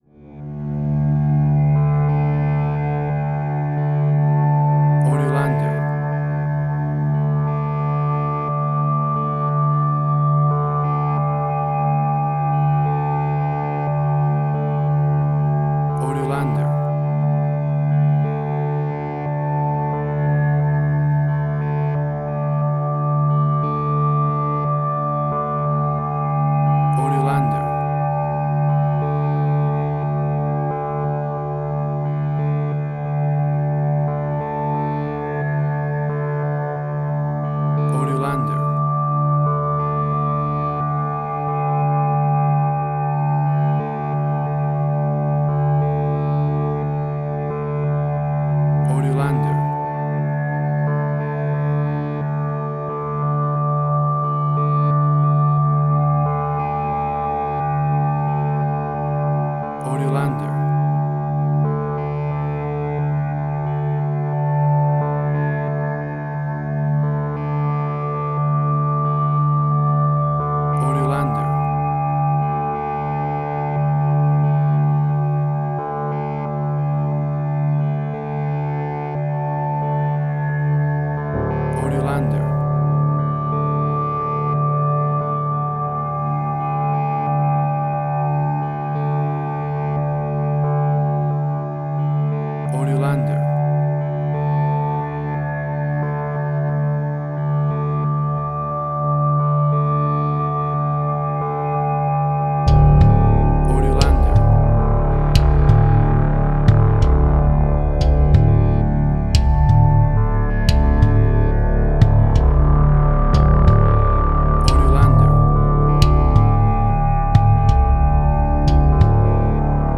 Suspense, Drama, Quirky, Emotional.
Tempo (BPM): 91